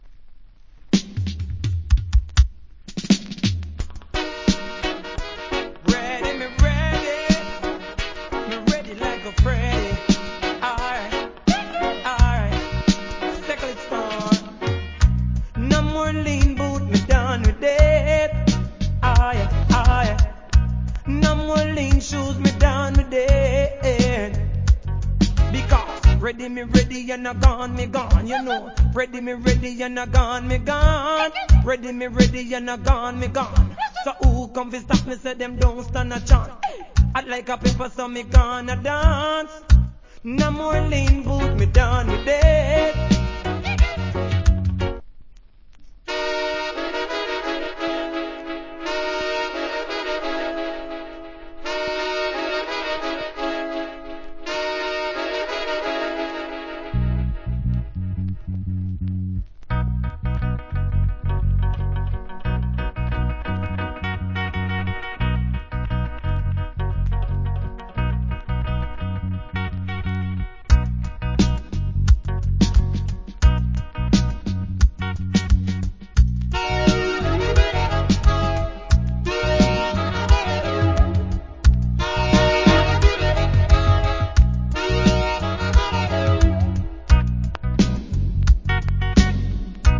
Dancehall Vocal.